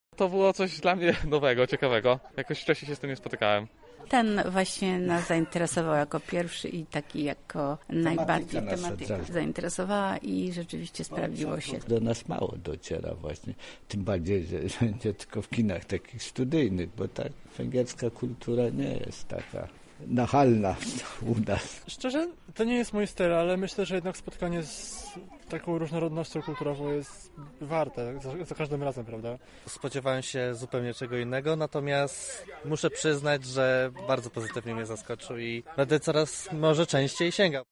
O wrażeniach po pierwszym dniu rozmawiał nasz reporter